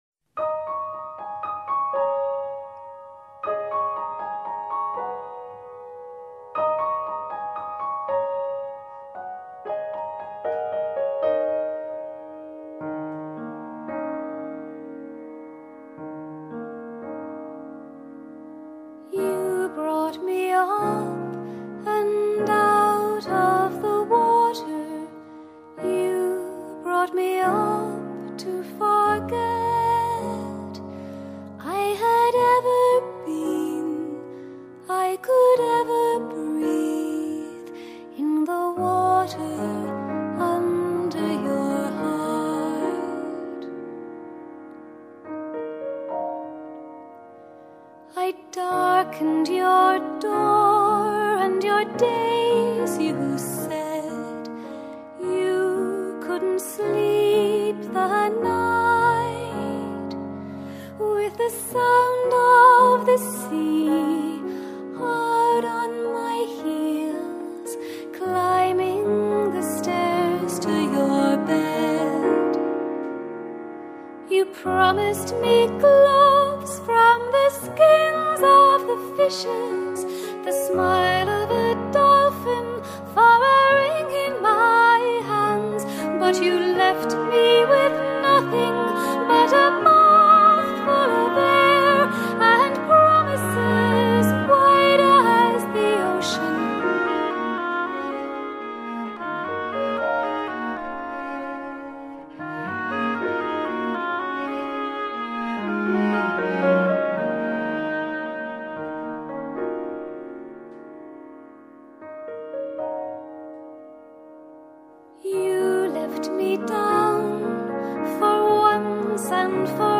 呈現出傳統、古典與當代完美融合的樂風。
純正的女聲擁有的就是那圓潤的嗓音，平進的聲線，音域飽滿而充滿濕潤感，甜美而舒服。
融入了一點美聲，因而演繹起凱爾特旋律特別恰當。